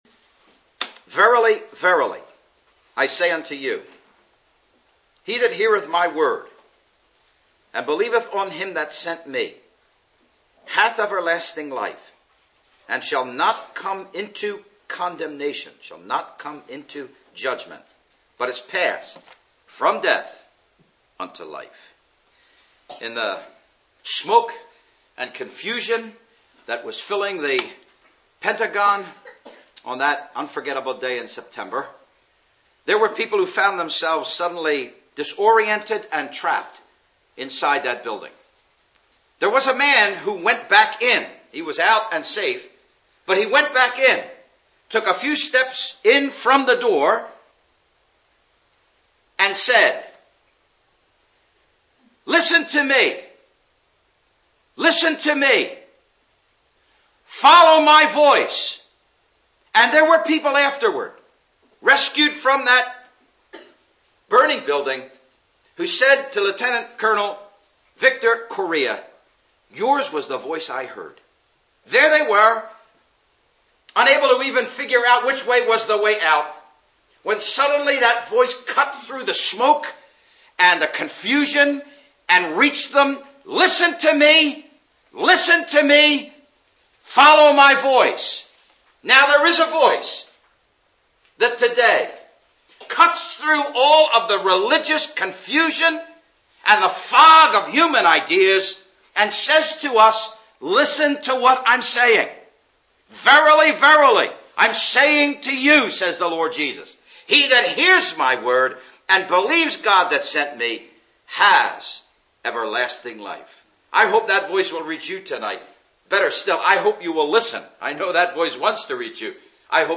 (Message preached 14th Nov 2008 in Ambrodsen Village Hall)